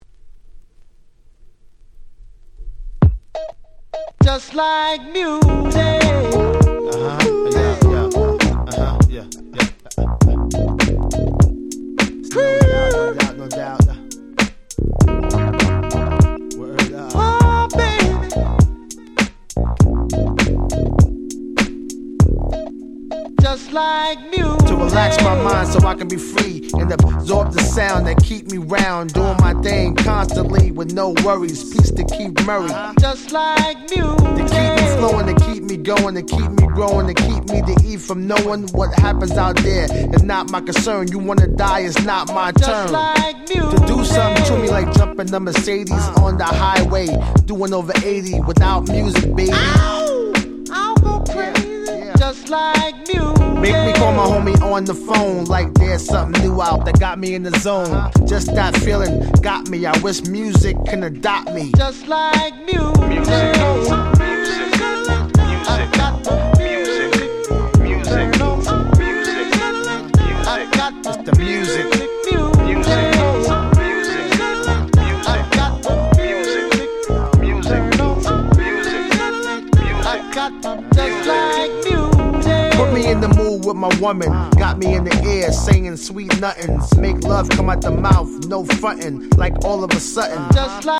全編Hip HopとR&Bのみの非常にBlackな内容。